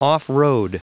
Prononciation du mot off-road en anglais (fichier audio)
Prononciation du mot : off-road
off-road.wav